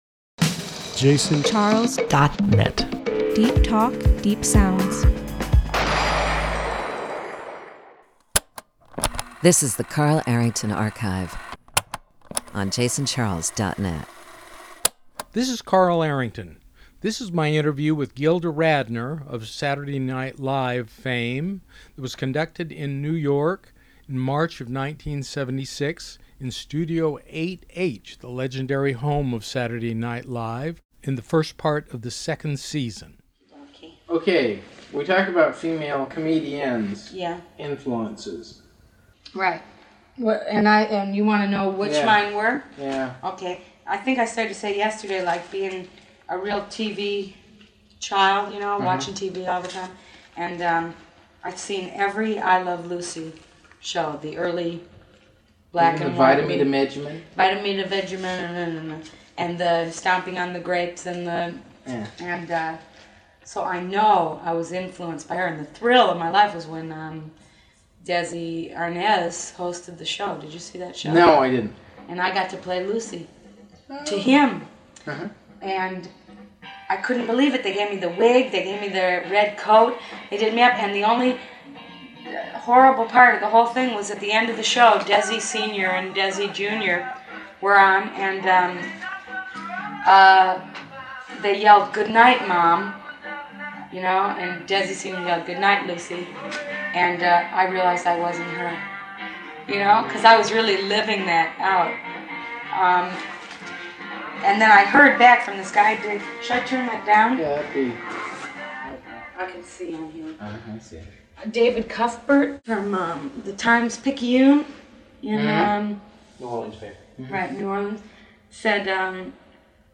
It was only the 2nd season and these conversations took place in Studio 8-H in the NBC building in between and after rehearsals for that week's show. Gilda was happy to talk about working with the Not Ready For Prime Time Players, favorite hosts and her comedic influences like Lucille Ball and Gracie Allen she watched on television growing up in Detroit, Michigan.